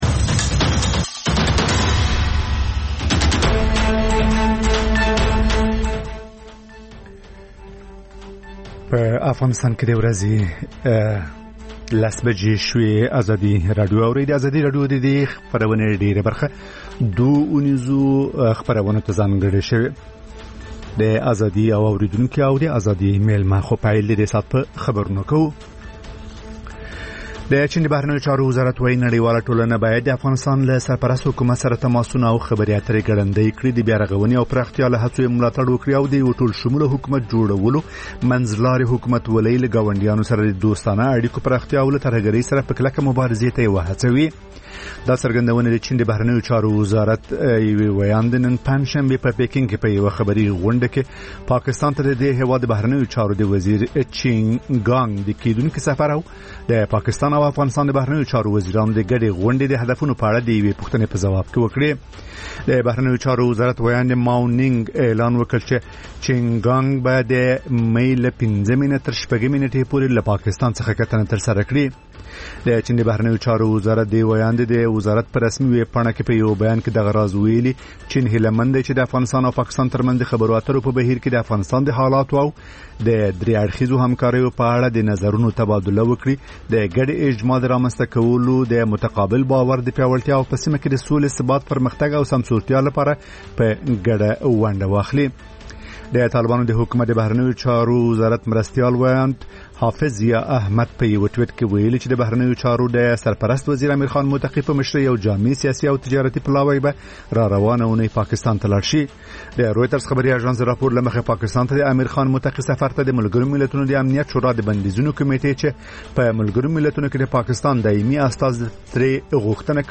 لنډ خبرونه - ازادي او اورېدونکي (تکرار)